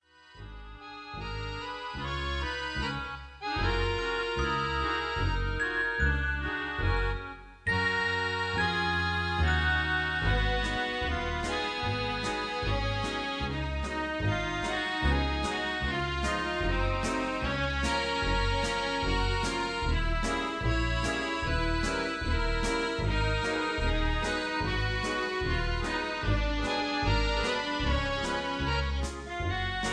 Karaoke MP3 Backing Tracks
Just Plain & Simply "GREAT MUSIC" (No Lyrics).
mp3 backing tracks